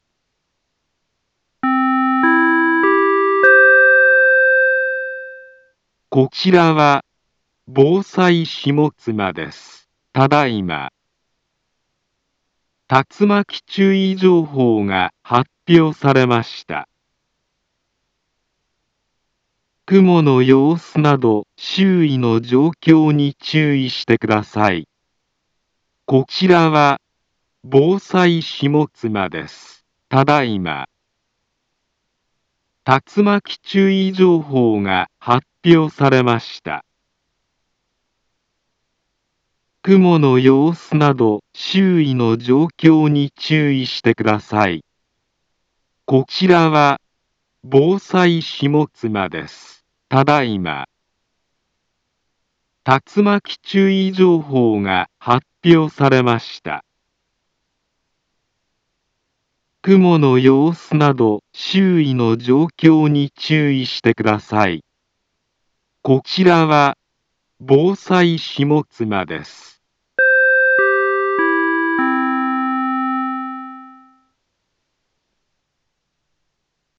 Back Home Ｊアラート情報 音声放送 再生 災害情報 カテゴリ：J-ALERT 登録日時：2021-12-01 08:09:55 インフォメーション：茨城県北部、南部は、竜巻などの激しい突風が発生しやすい気象状況になっています。